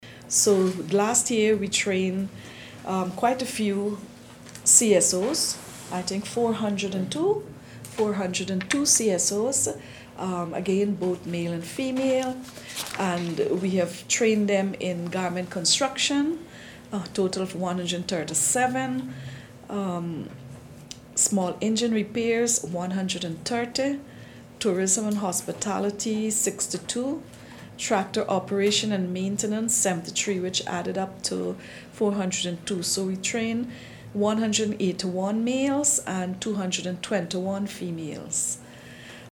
Hon. Pauline Sukhai Minister of Amerindian Affairs
Minister Pauline Sukhai shared the achievements in a press briefing.